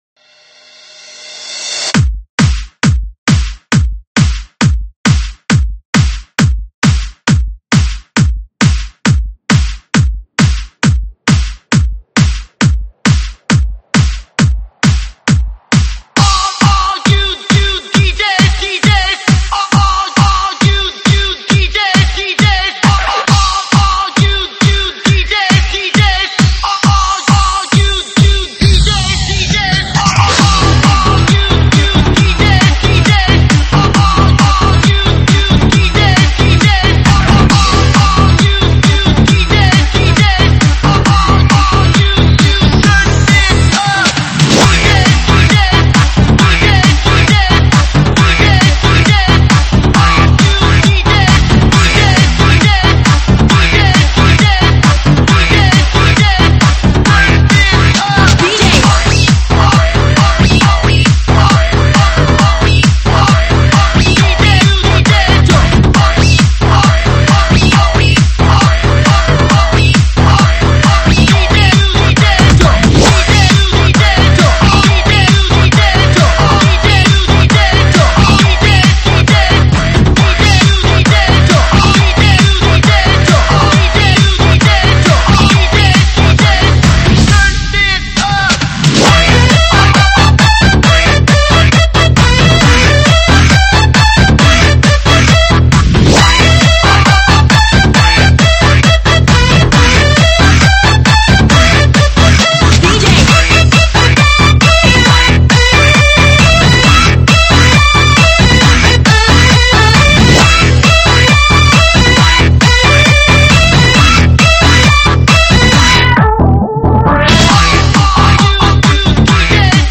栏目：英文舞曲